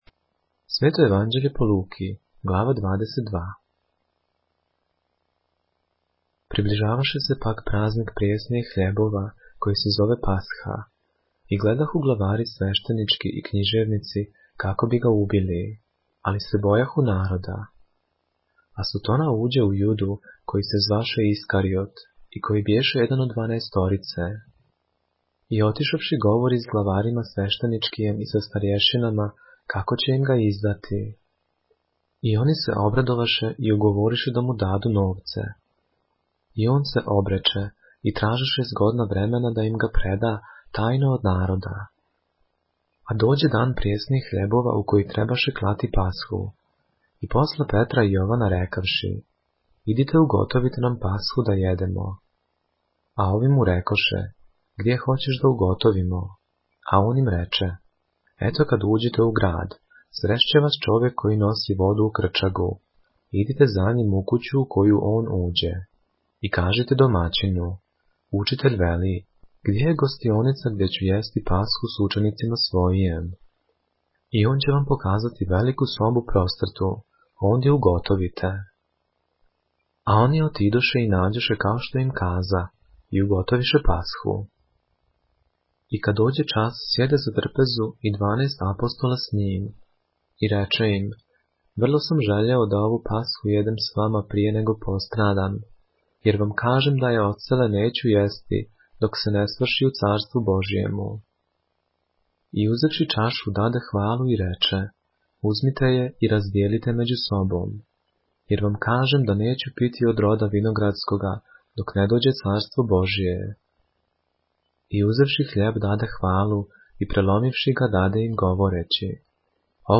поглавље српске Библије - са аудио нарације - Luke, chapter 22 of the Holy Bible in the Serbian language